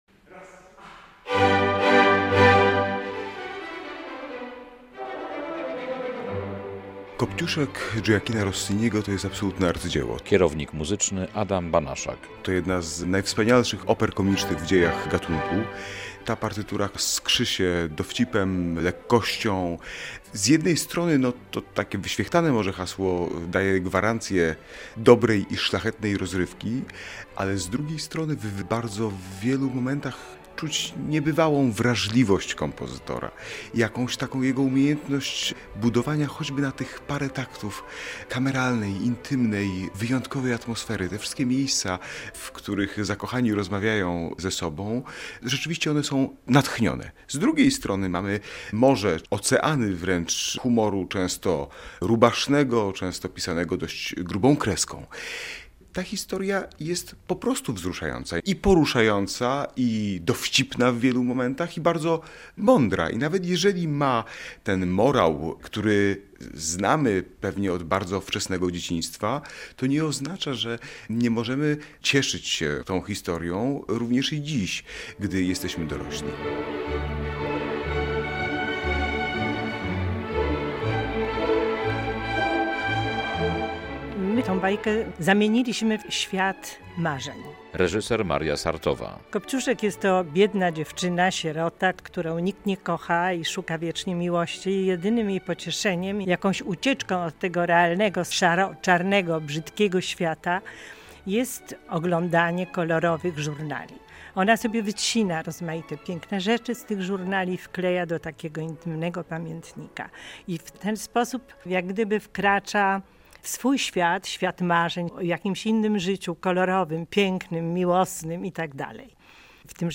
relacja
odwiedził białostocką operę, gdzie rozpoczęły się próby i porozmawiał z twórcami spektaklu.